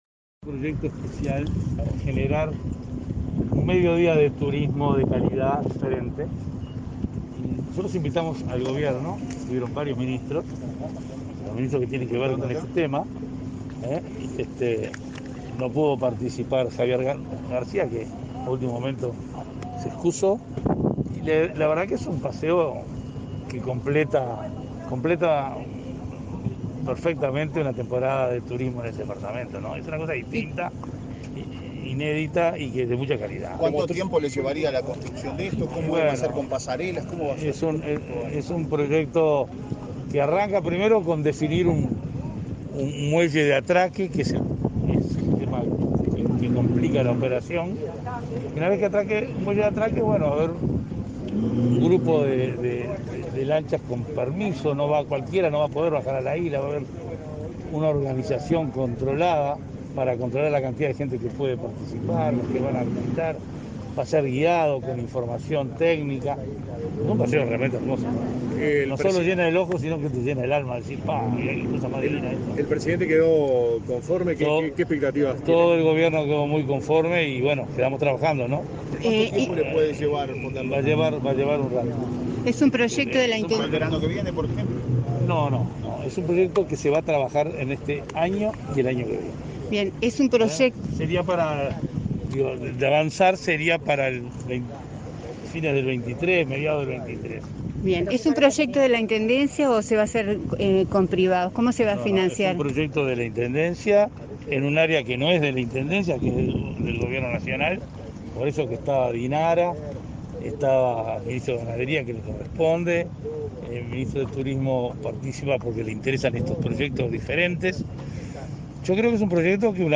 Declaraciones de prensa del intendente de Maldonado, Enrique Antía